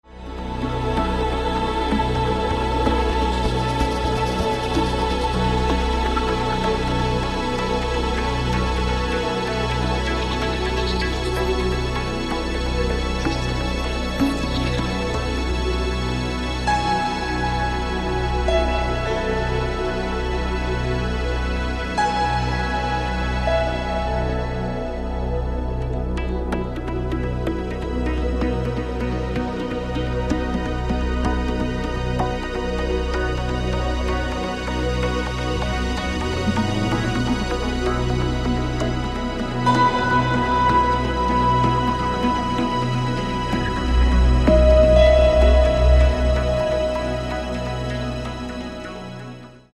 Каталог -> Джаз та навколо -> Міські суміші